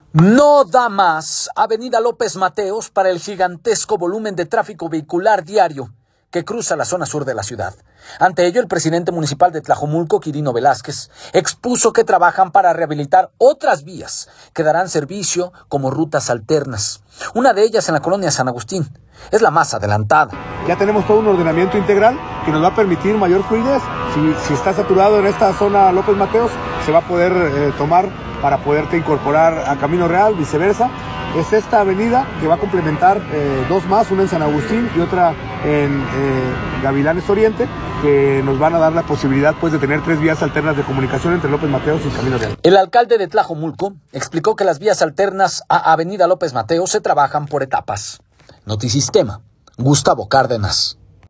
No da más avenida López Mateos para el gigantesco volumen de tráfico vehicular diario en la zona sur de la ciudad, ante ello el presidente municipal de Tlajomulco, Quirino Velázquez expuso que trabajan para rehabilitar otras vías que darán servicio como rutas […]